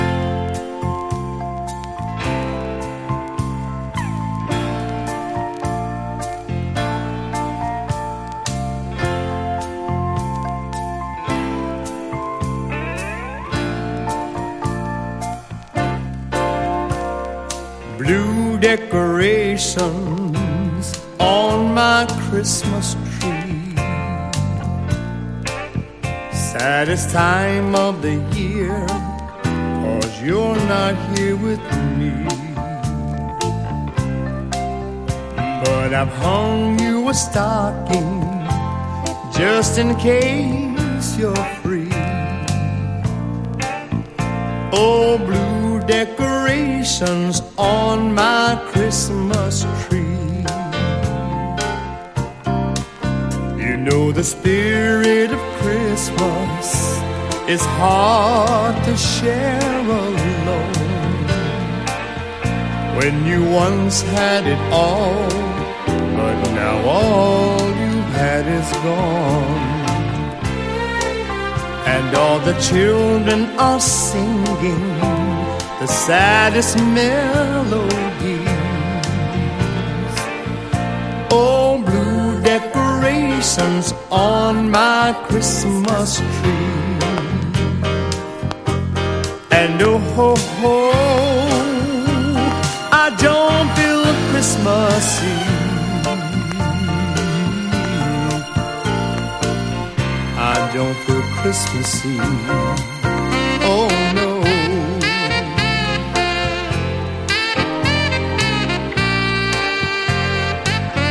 黄昏ムードのクリスマス・ソウル・バラッド